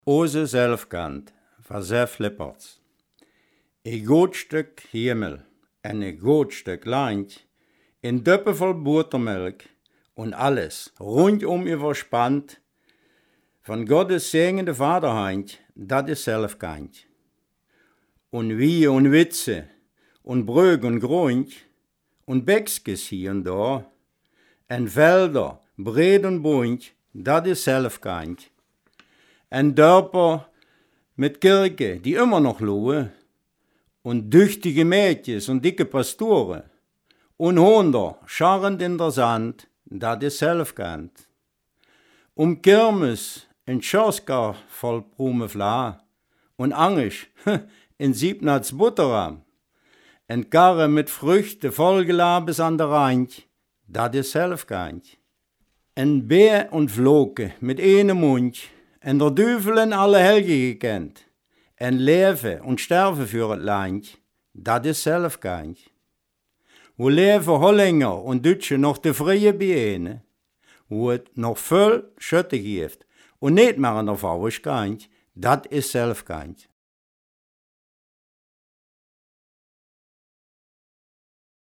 Selfkant-Platt
Gedicht